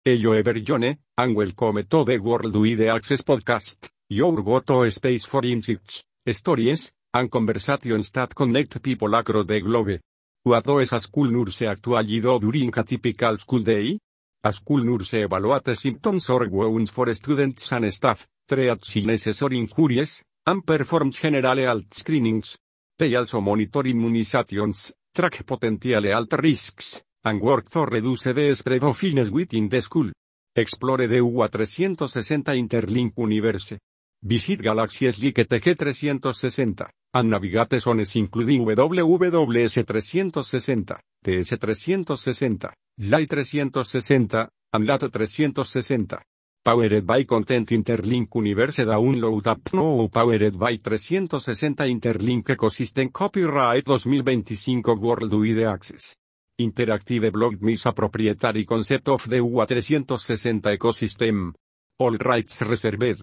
narration-18.mp3